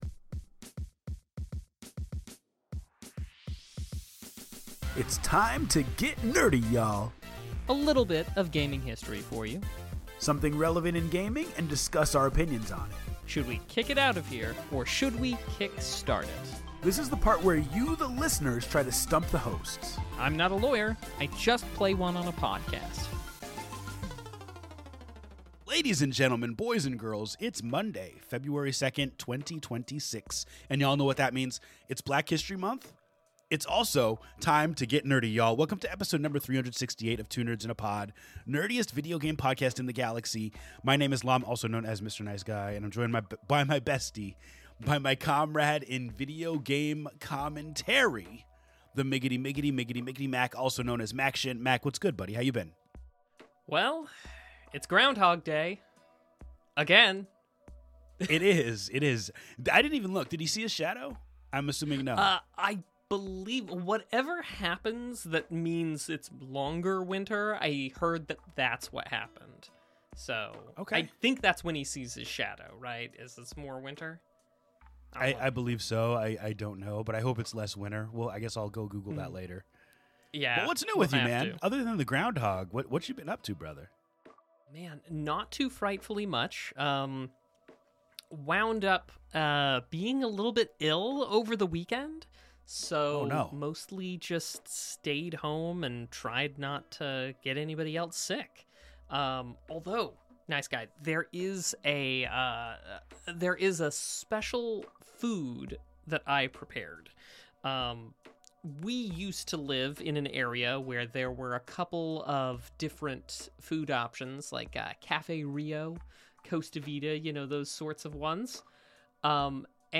gaming talk show